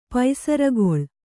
♪ paisaragoḷ